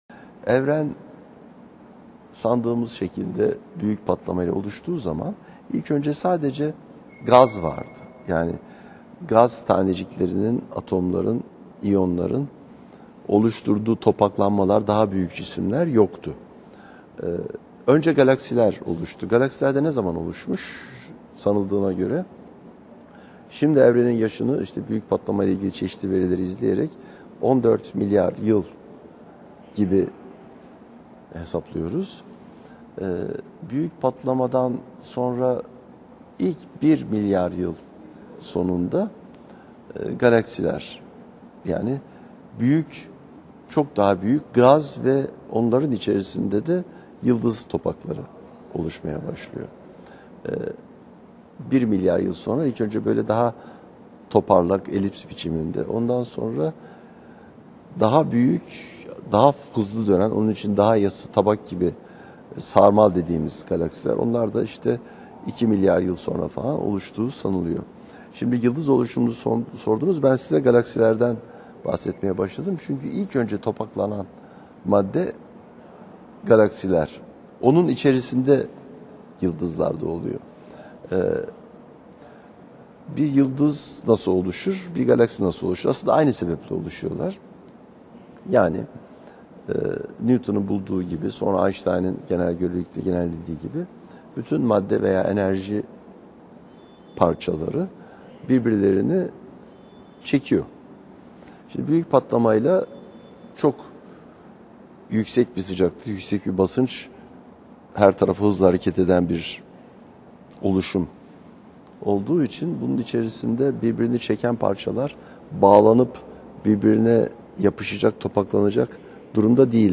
Ali Alpar, Emin Çapa ile sohbetinde Büyük Patlama sonrası olan süreci, yıldızların oluşumunu, yıldızlar oluştuktan sonra yakıtlarını nasıl kullandıklarını ve tükettiklerini ve canlıları oluşturan organik madde dahil, periyodik tablodaki elementlerin neredeyse tamamının yıldızlarda oluştuğunu anlatıyor.